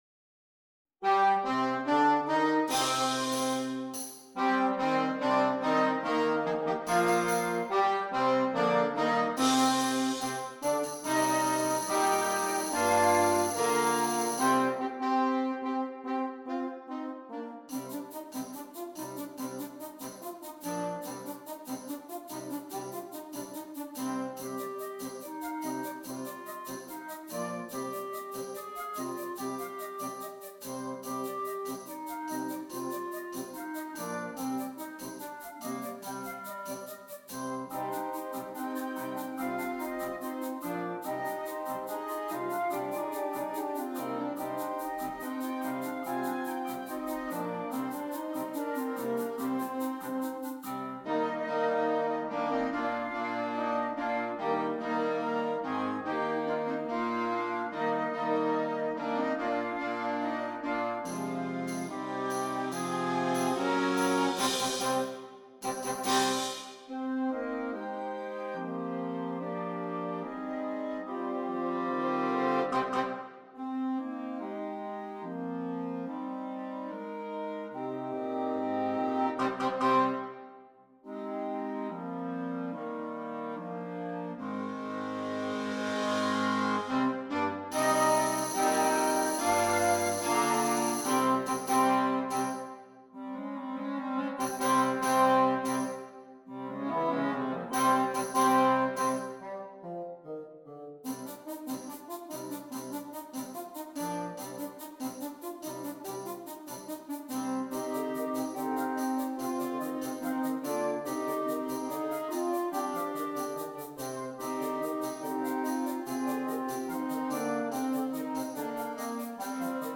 Interchangeable Woodwind Ensemble
The music is always moving forward-advancing.